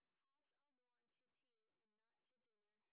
sp14_street_snr30.wav